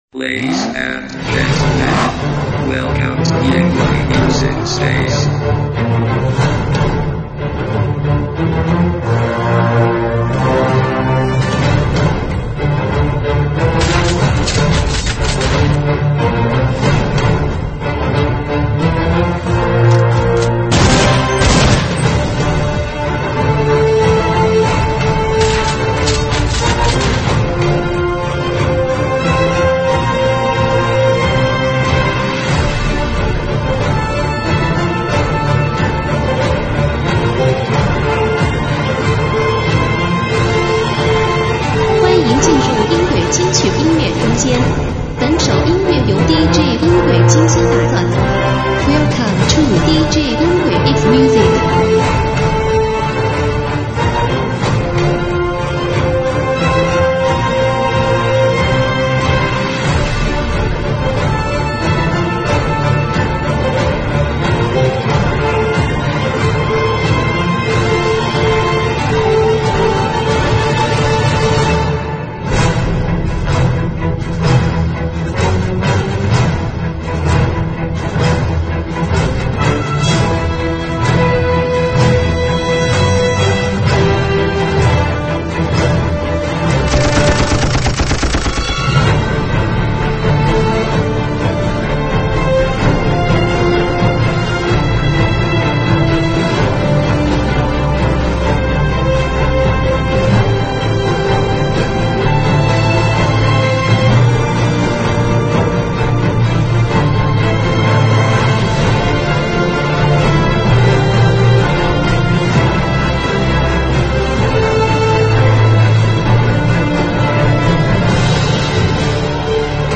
舞曲类别：3D全景环绕